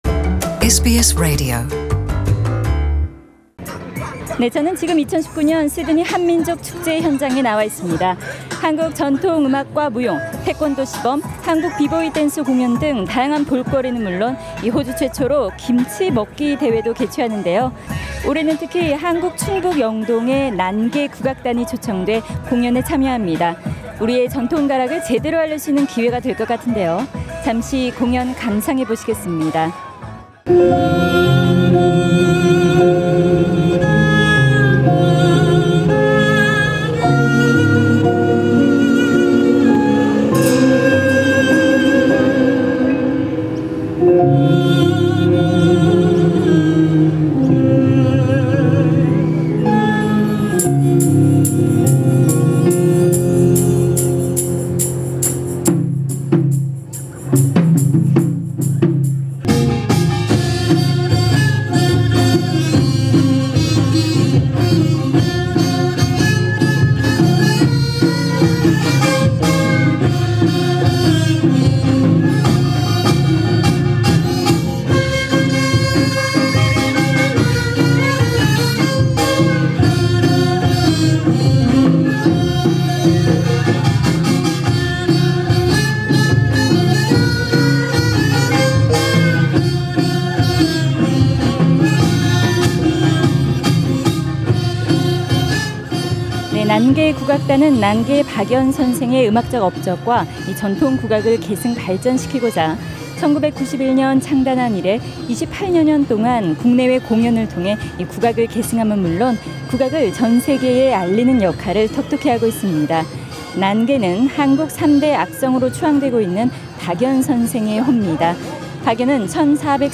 SBS Radio Korean Program conducted an interview